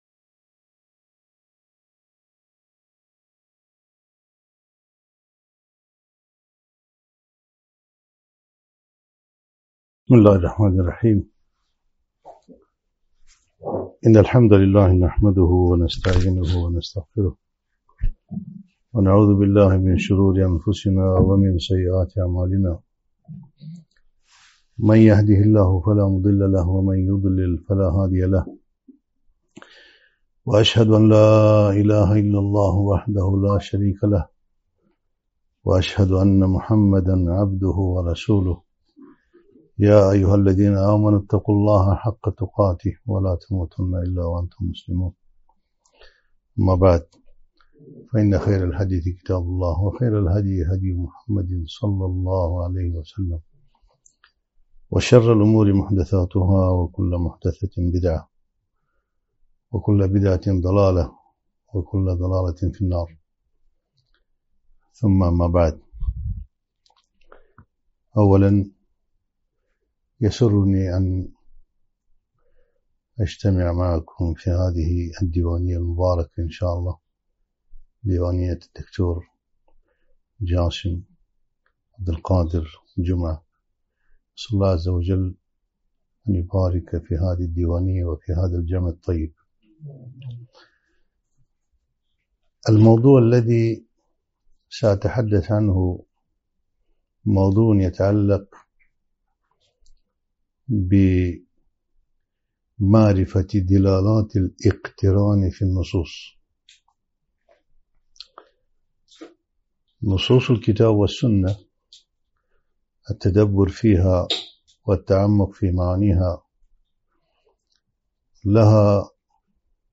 محاضرة - الجمع بين الحمد والملك في النصوص